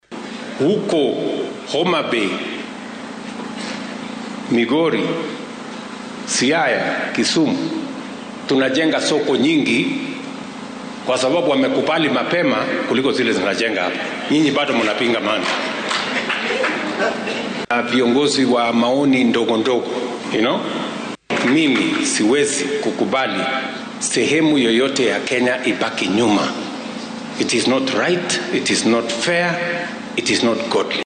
Isagoo Sabtidii ka hadlayay kuliyadda isku dhafka ah ee Eastern Kenya ee ku taal magaalada Mitaboni, ee ismaamulka Machakos ayuu sheegay in aan qeyb ka mid ah dalka laga reebi doonin horumarka.